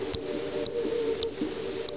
Klänge der Fledermäuse
Myotis auriculus und a
Alle Rufe auf dieser Seite wurden mit einem Petterson D-980 Fledermaus Detektor aufgezeichnet und mit einem Faktor 10 verlangsamt.
Die Klänge sind mit einer Auflösung von 8 bit bei 44,1 kHz auf einer Soundkarte resampled.